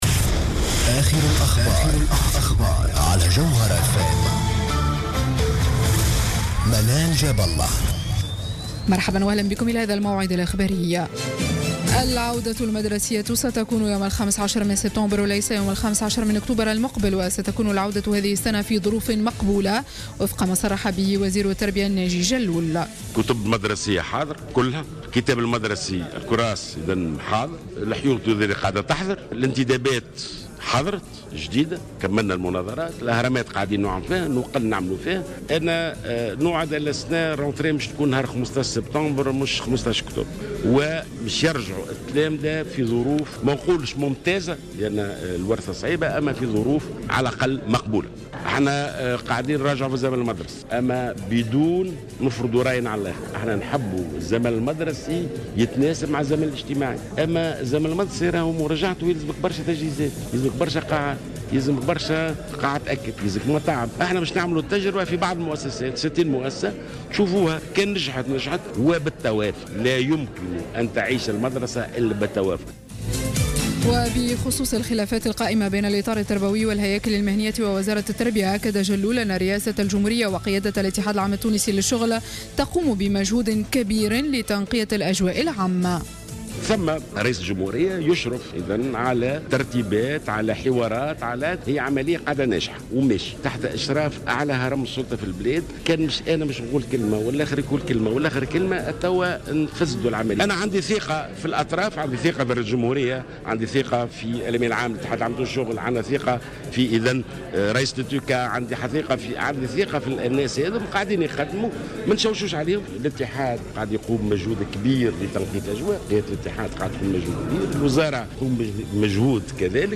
نشرة أخبار منتصف الليل ليوم الجمعة 21 أوت 2015